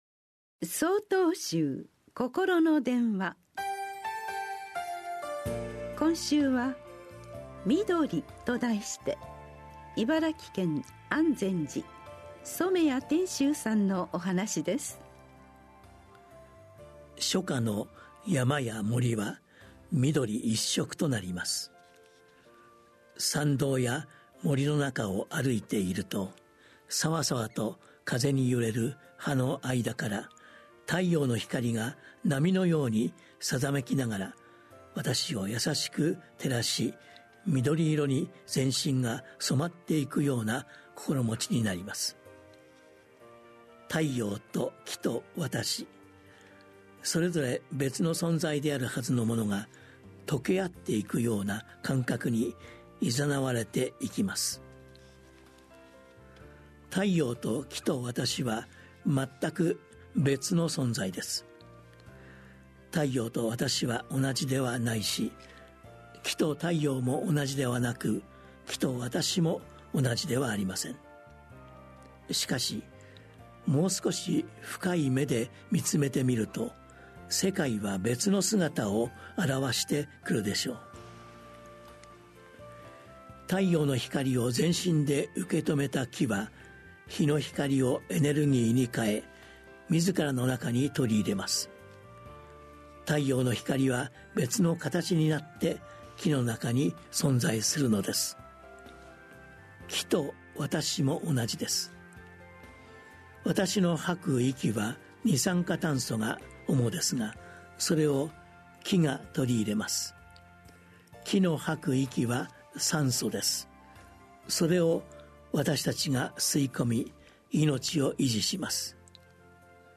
曹洞宗では毎週、わかりやすい仏教のお話（法話）を、電話と音声やポッドキャストにて配信しています。